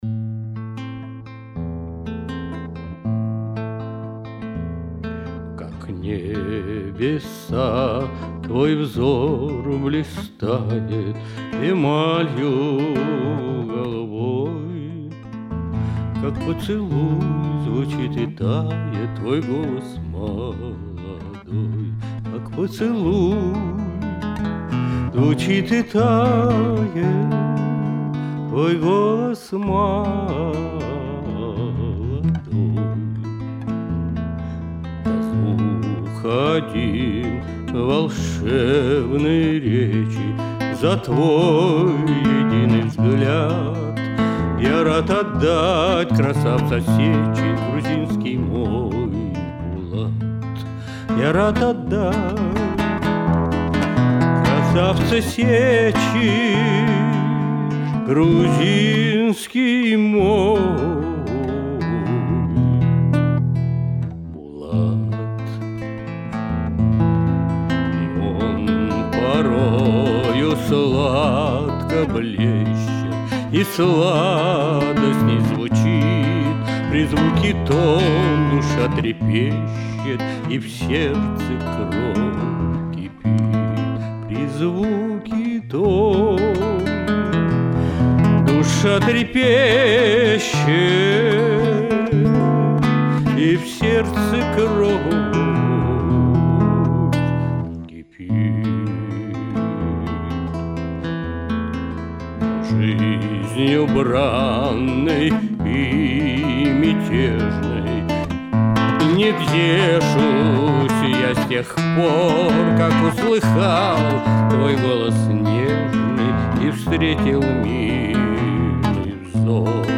вокал, гитара
Записано в студии